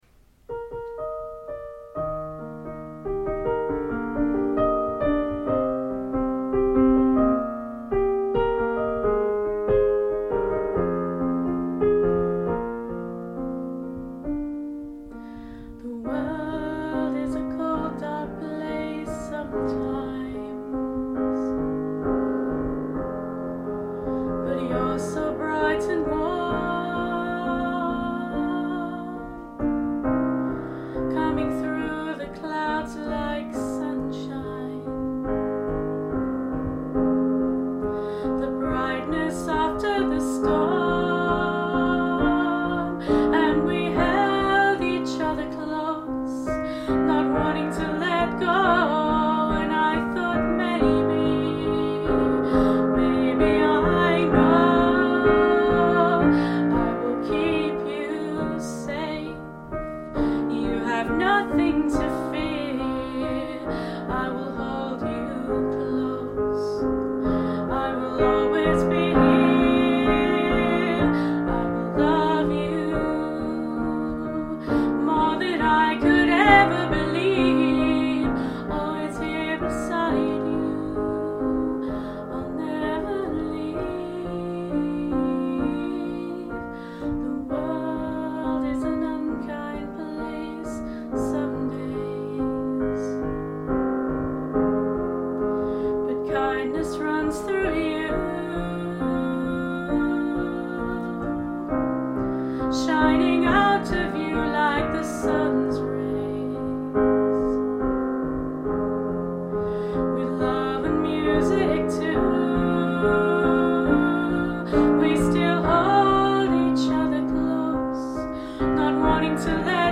Keep You Safe: a rough demo of my latest song